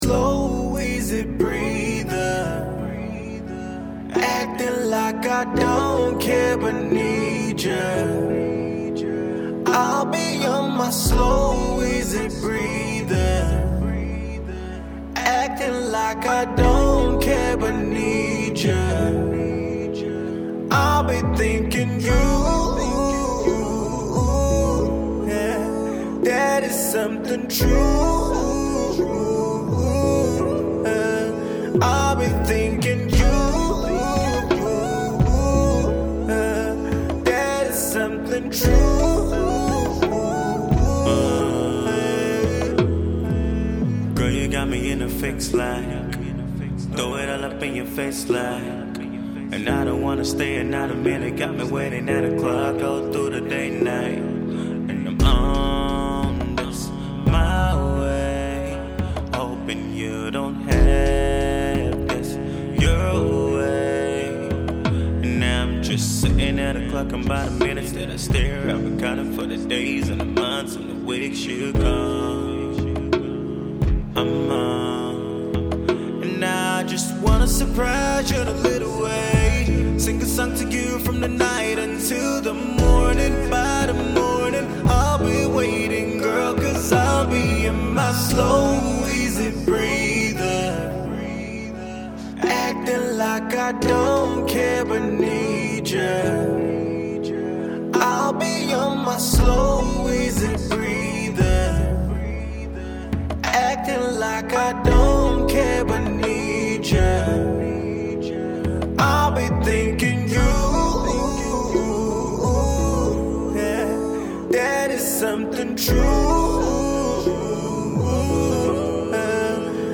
Alternative SoulAudioBluesTrippy Blues
It’s a mellow R&B song which I like, check it out below.